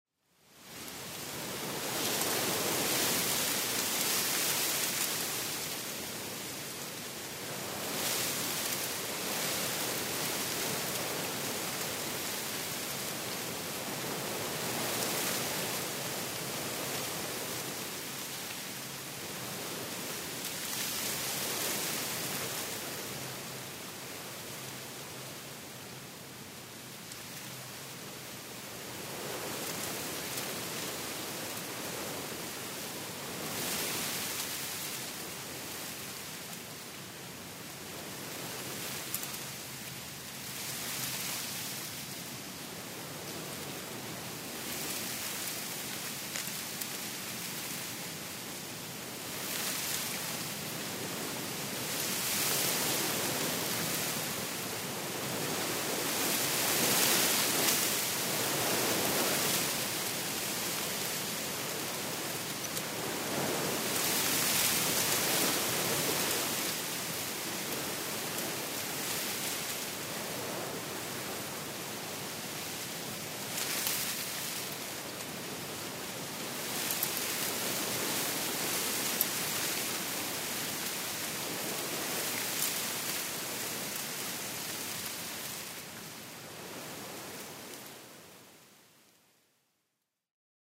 – Les roselières en Camargue
ROSELIÈRES EN CAMARGUE
roseliere-camargue.mp3